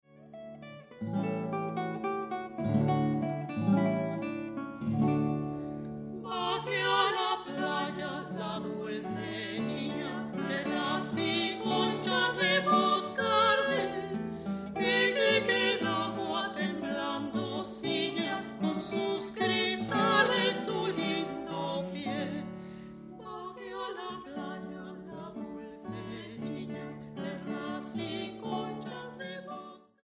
guitarras